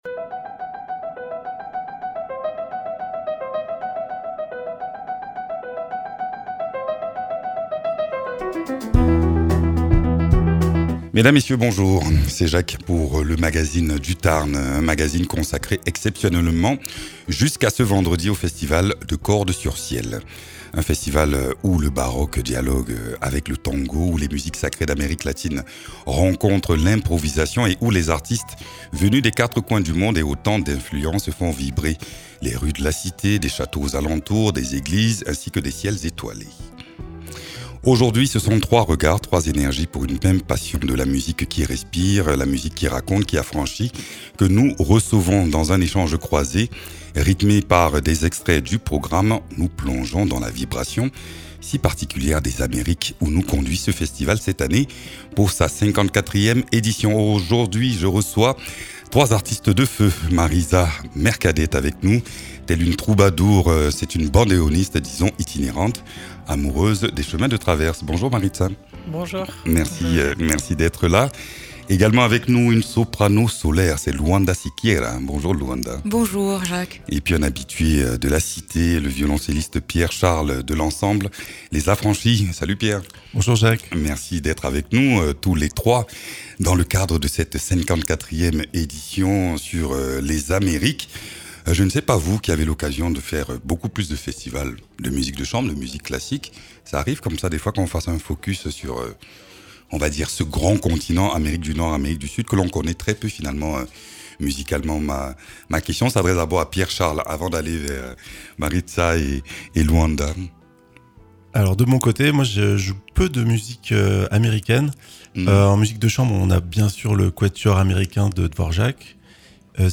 Pour sa 54e édition, le festival de Cordes sur Ciel explore les sonorités des Amériques et les répertoires qui bousculent les habitudes. Nos invité.e.s partagent ici leur passion commune pour les musiques vivantes, croisées, affranchies des frontières stylistiques. Baroque sud-américain, tango réinventé, voix sacrées et improvisation : un dialogue vibrant, au micro comme en concert.